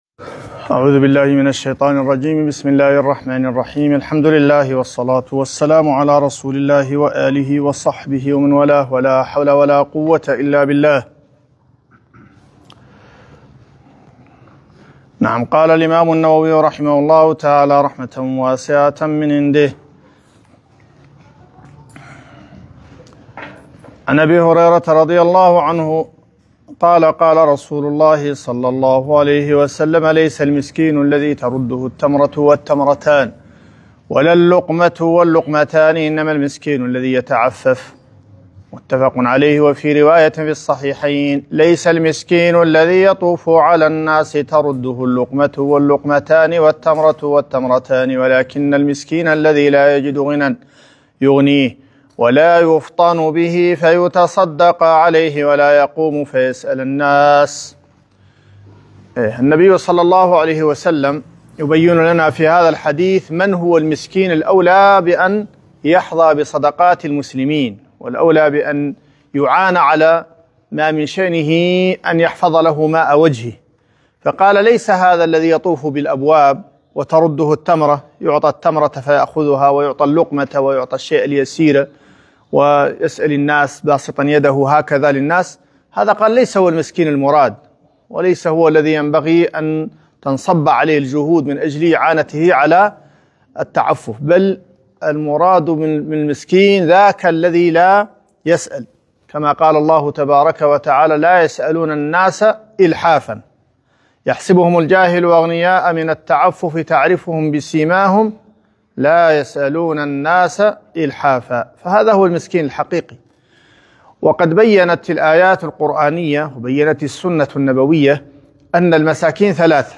رياض الصالحين الدرس 20